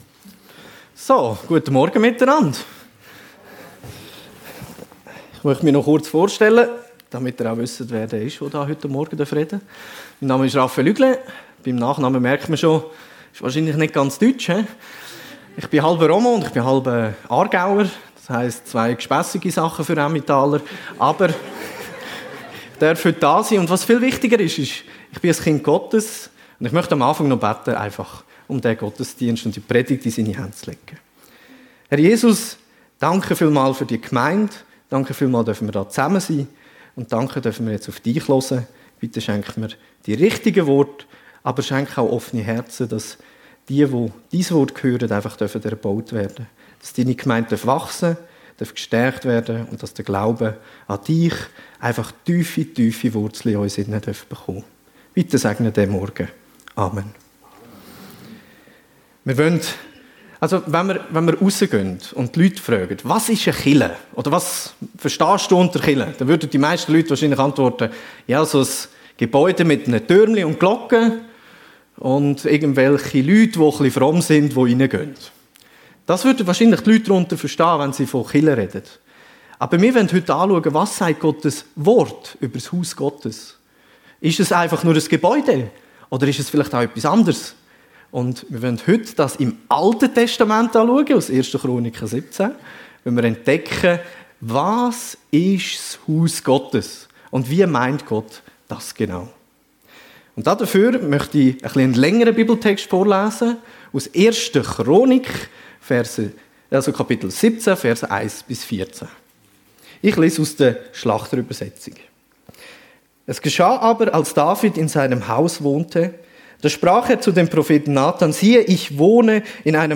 Was ist das Haus Gottes? ~ FEG Sumiswald - Predigten Podcast